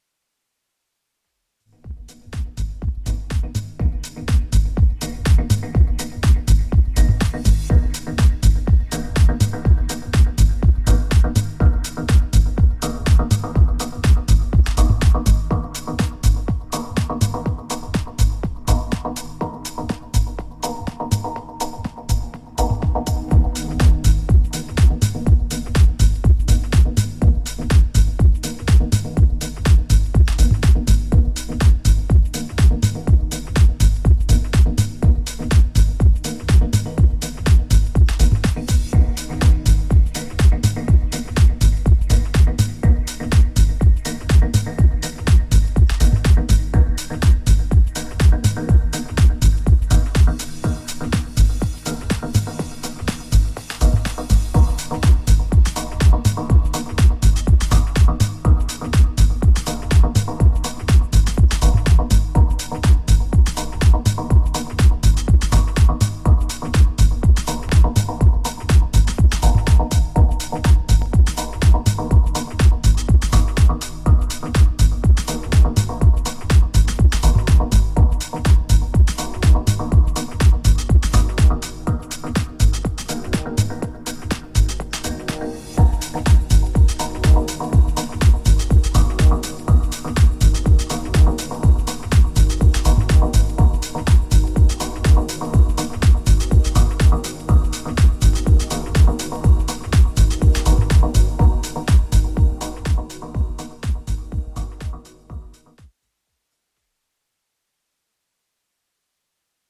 ジャンル(スタイル) TECH HOUSE / MINIMAL HOUSE